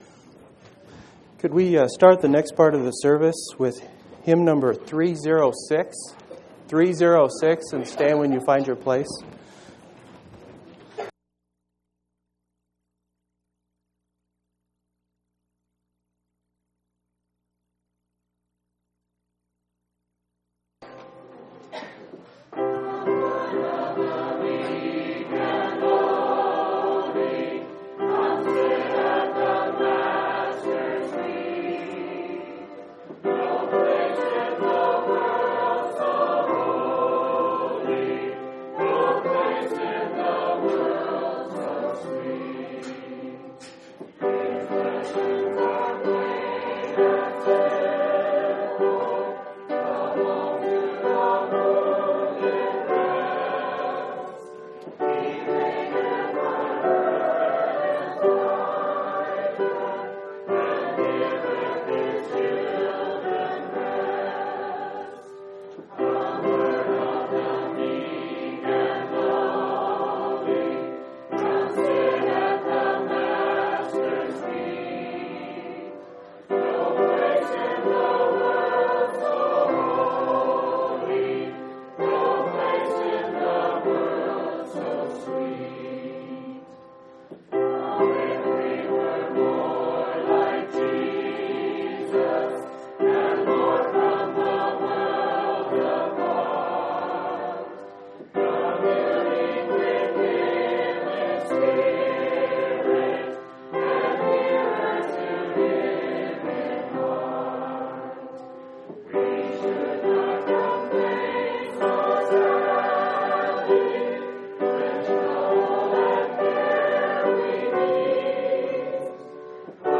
11/28/2003 Location: Phoenix Reunion Event: Phoenix Reunion